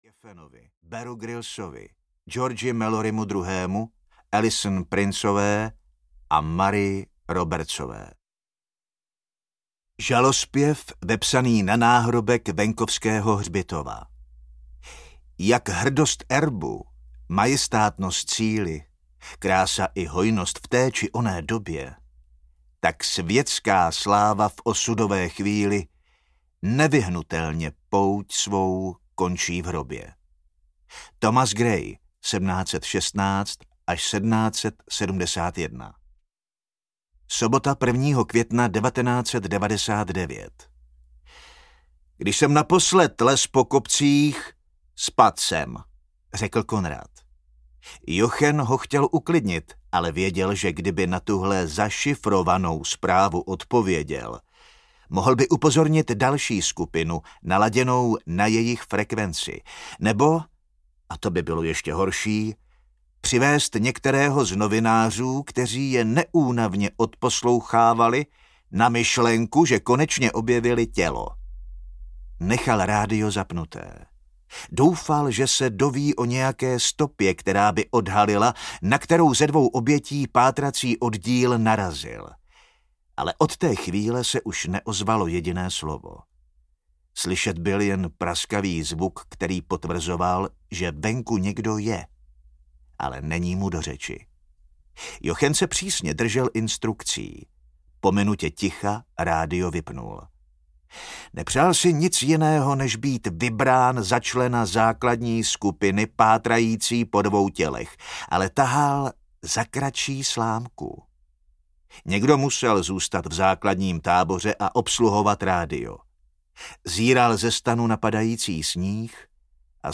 Cesta slávy audiokniha
Ukázka z knihy
cesta-slavy-audiokniha